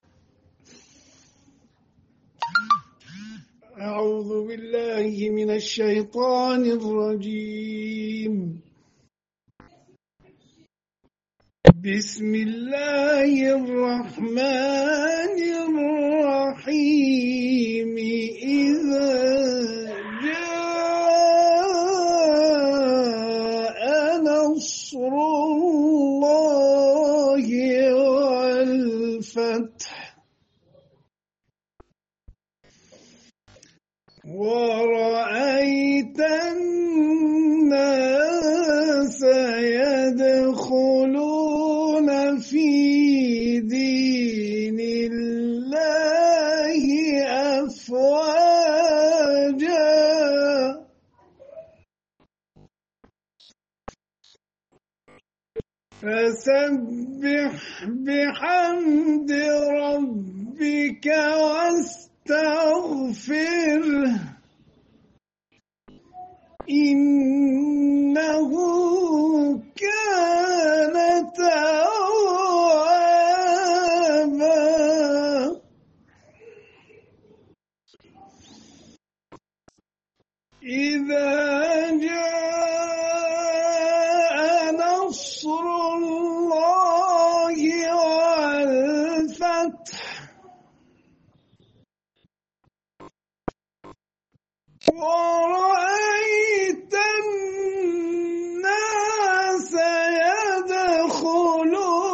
د مصر د یوه قاری په زړه پورې غږ سره د نصر سورې تلاؤت
بچوې: قرآنی خبرونه ، قرآنی هنرونه ، تلاوت ، قاریان ، حافظان ، مصر ، نصر ، سوره ، ایران ، وحشی اسراییل ، حمله ، امریکا